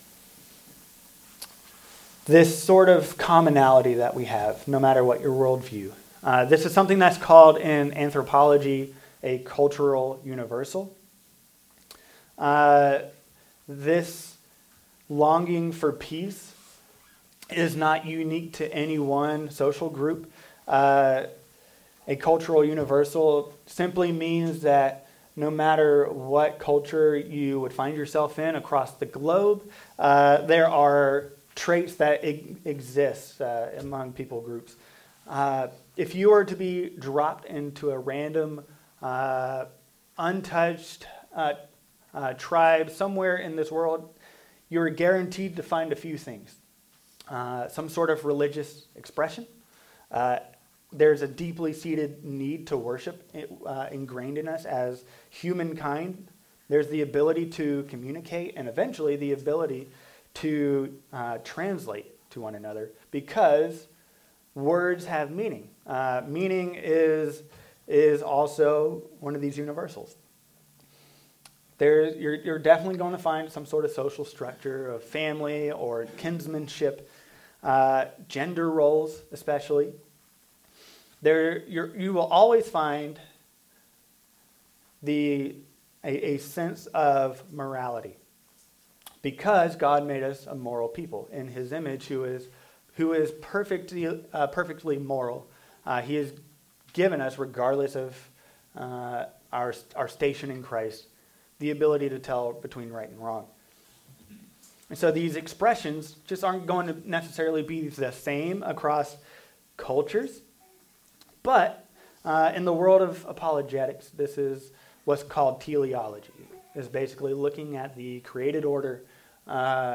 Luke 2:12-14 // The Advent of Peace (partially recorded) The Divine Dilemma The Incarnation of Peace
All Sermons